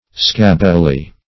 scabbily - definition of scabbily - synonyms, pronunciation, spelling from Free Dictionary Search Result for " scabbily" : The Collaborative International Dictionary of English v.0.48: Scabbily \Scab"bi*ly\, adv.